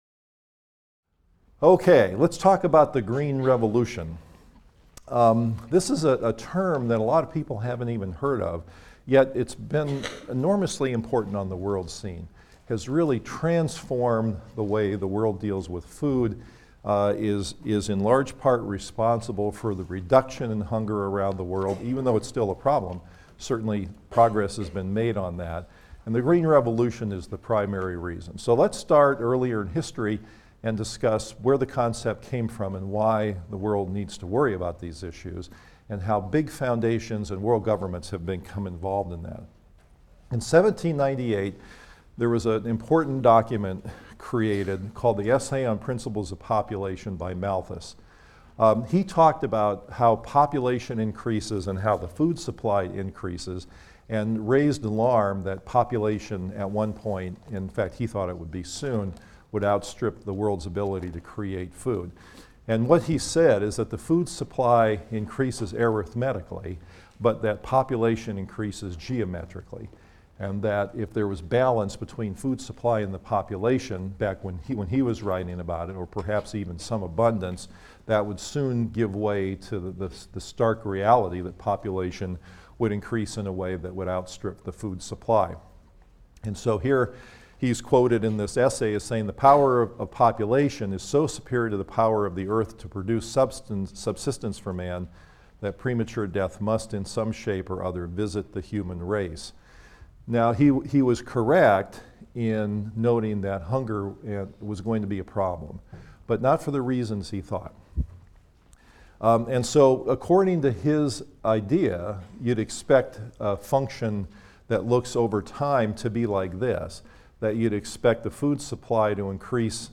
PSYC 123 - Lecture 9 - From Ancient to Modern Farming: The Green Revolution and the Prospect of Feeding the World | Open Yale Courses